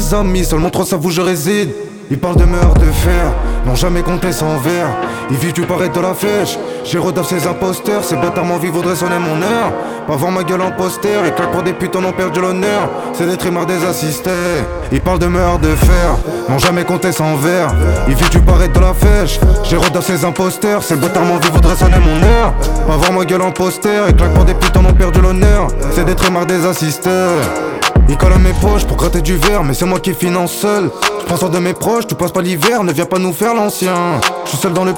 Жанр: Хип-Хоп / Рэп / R&B / Соул